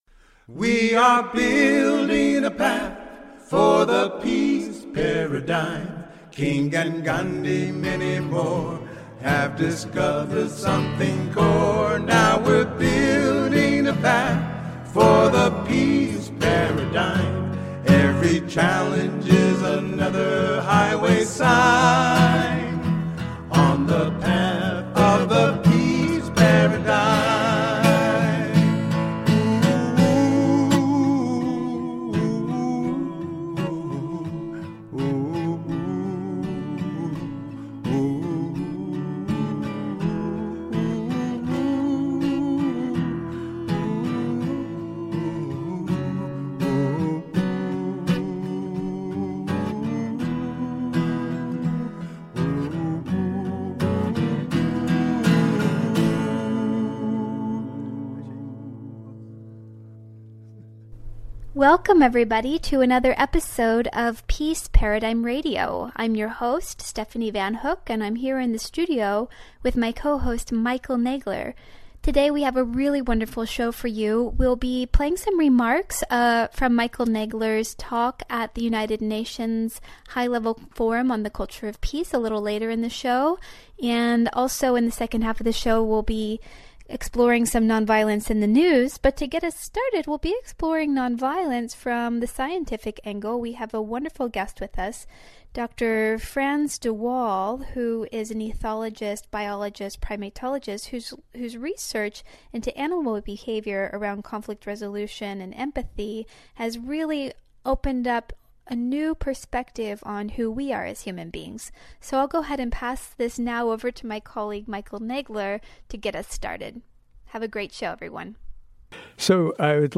The distinguished ethologist and biologist Frans de Waal, who won the 2012 Nobel in Anatomy, joins to discuss some of his fascinating research into animal behavior, specifically around reconciliation, conflict resolution, empathy, rituals, and even death.